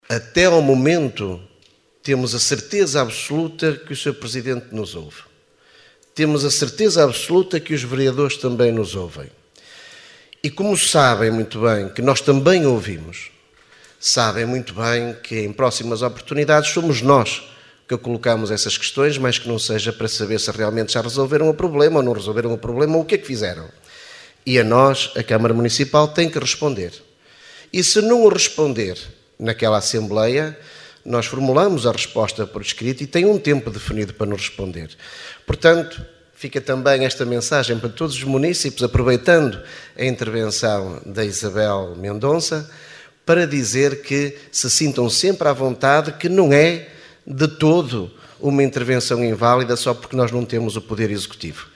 Excertos da última assembleia municipal, realizada a 16 de Dezembro no Teatro Valadares em Caminha.